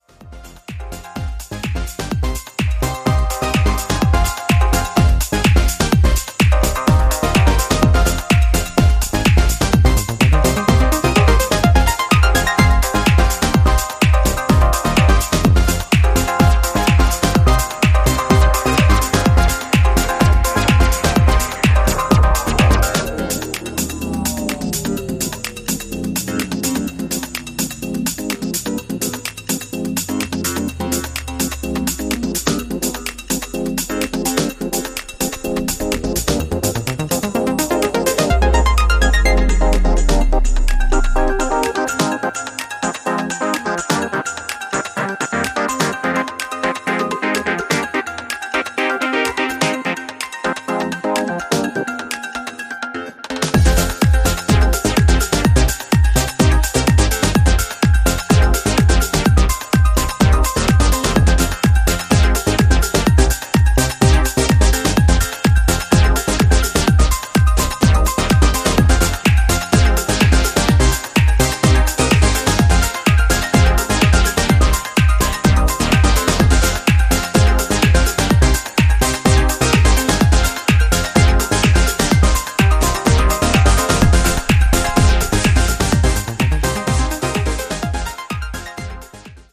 ここでは、豊潤なシンセレイヤーが躍動するメロディックかつエネルギッシュなハウス・トラックスを展開。